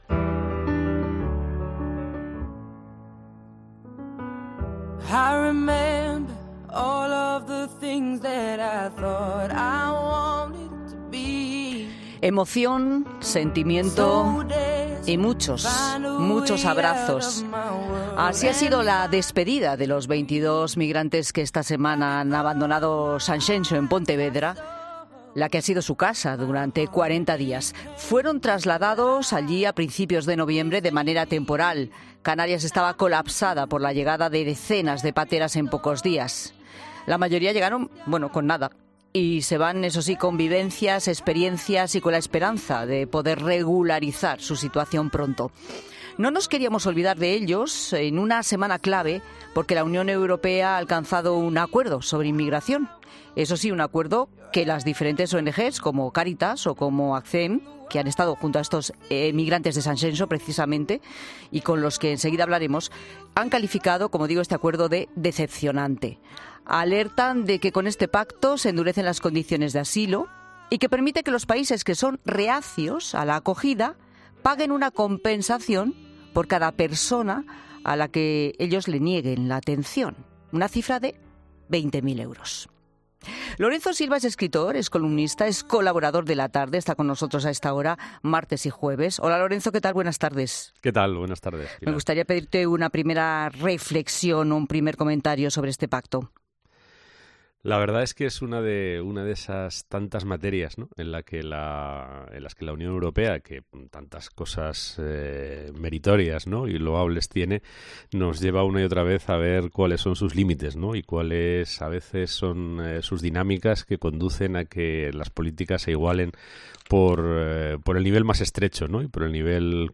Hemos tenido la oportunidad de conocer las vivencias de nuestros dos invitados.